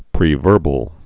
(prē-vûrbəl)